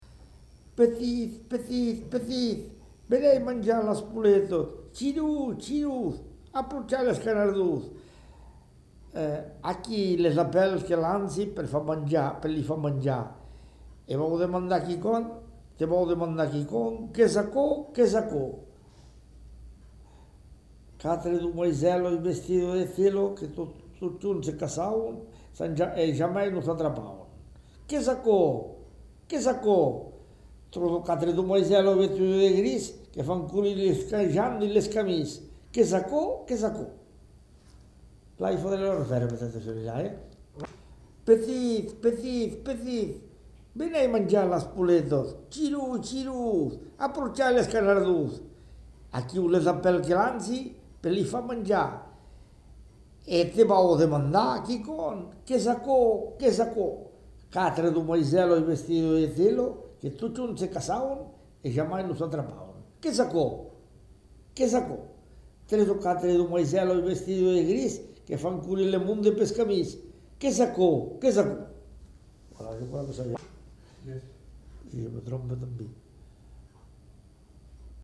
Appel au bétail
Lieu : Caragoudes
Genre : expression vocale
Effectif : 1
Type de voix : voix d'homme
Production du son : crié